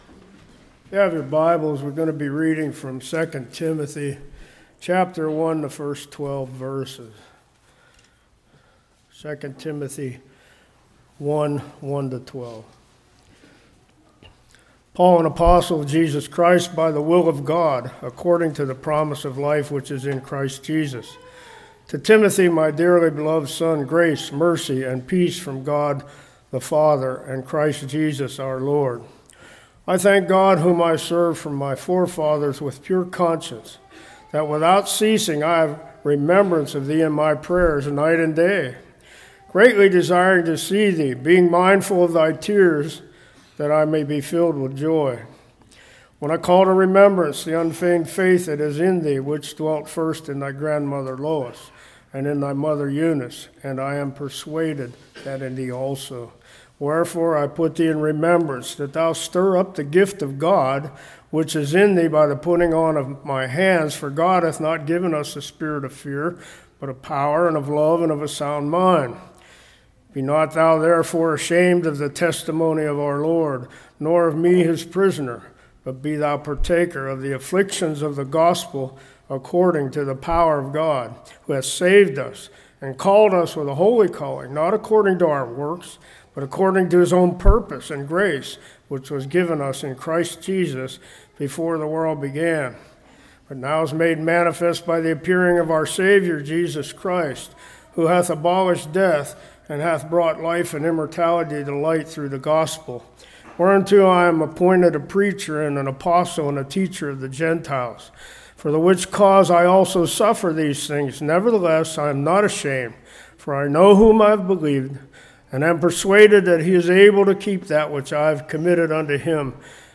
2 Timothy 1:1-12 Service Type: Morning Promise of Life.